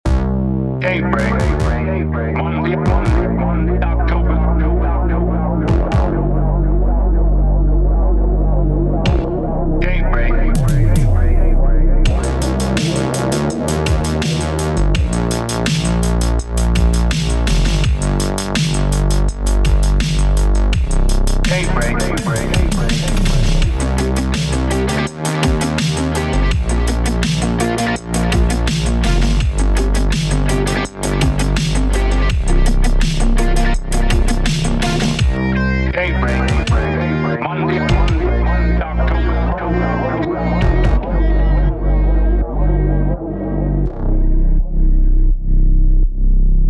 A short, dramatic instrumental song. It starts with a male voice announcing: "Daybreak, Monday, October..."